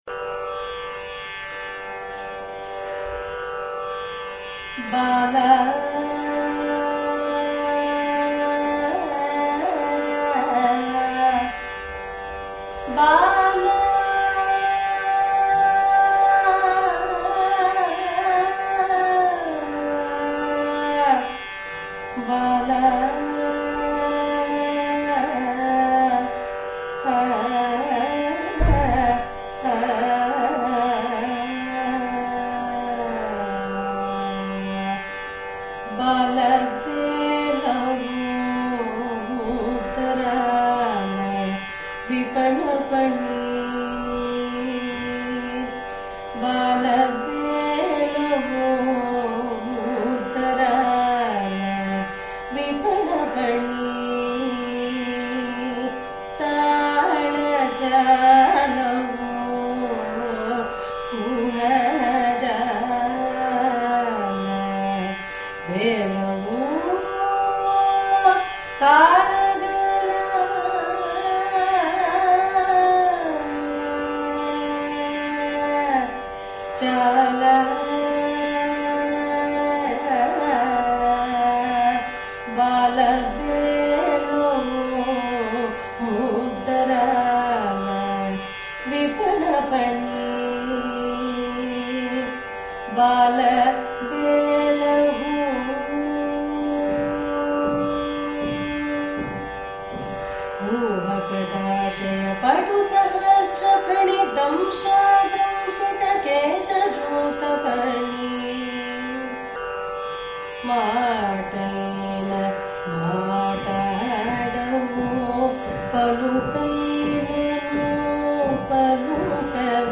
రాగం: నీలాంబరి
తాళం: రూపక